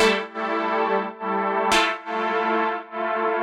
Index of /musicradar/sidechained-samples/140bpm
GnS_Pad-MiscA1:2_140-A.wav